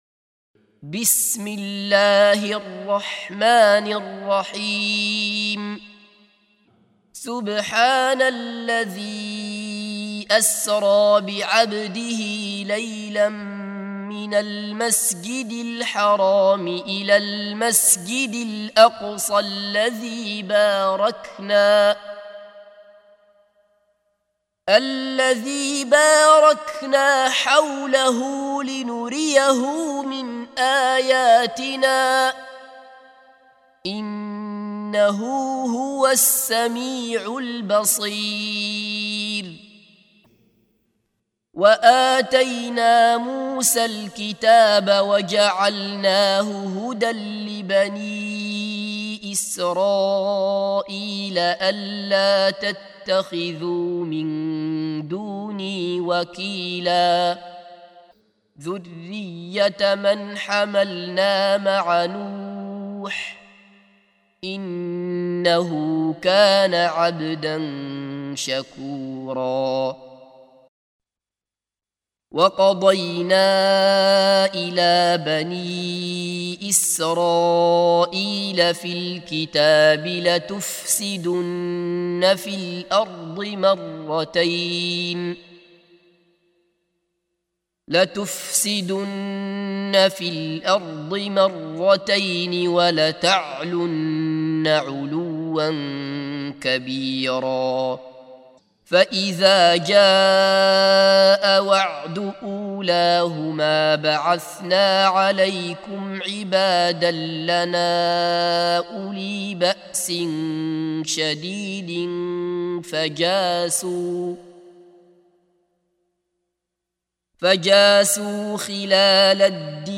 سُورَةُ الإِسۡرَاءِ بصوت الشيخ عبدالله بصفر